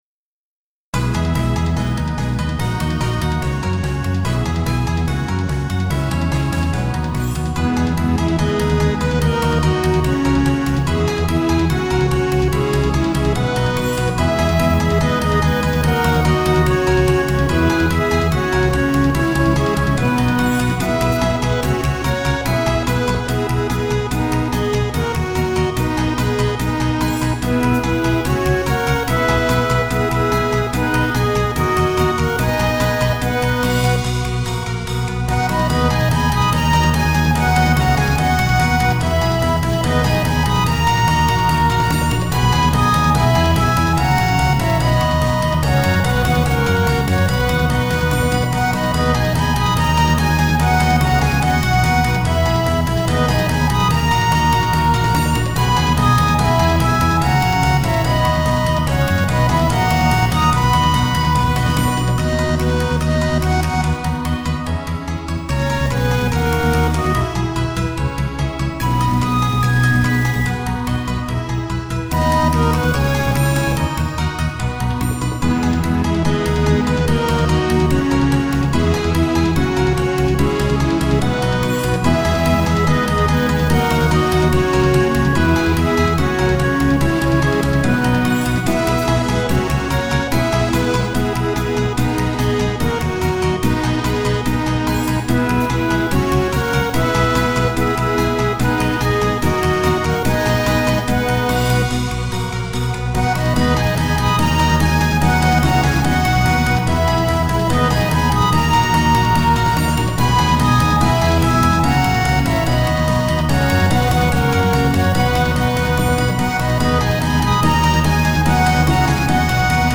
ロ長調